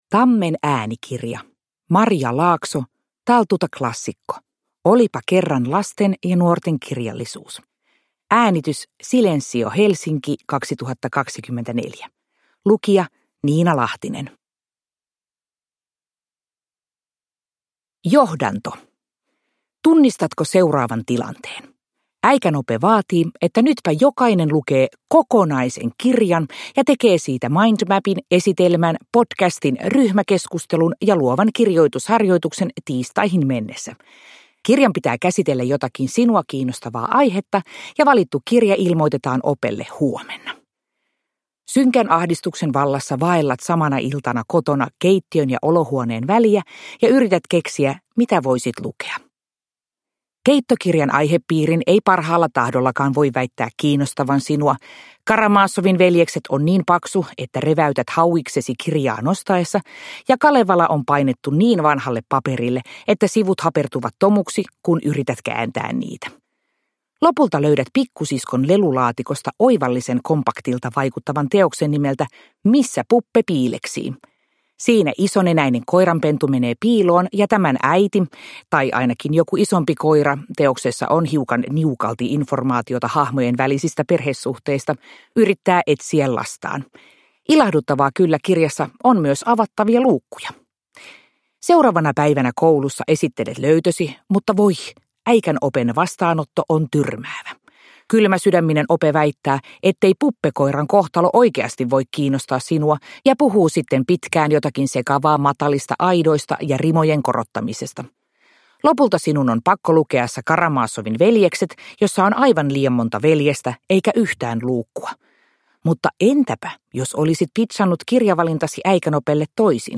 Taltuta klassikko! Olipa kerran lasten- ja nuortenkirjallisuus – Ljudbok